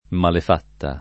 DOP: Dizionario di Ortografia e Pronunzia della lingua italiana
malef#tta] o malafatta s. f.; pl. malefatte — nel sing., più com. malafatta nel sign. materiale e proprio («errore di tessitura»), più com. malefatta nel sign. morale e generico («cattiva azione»)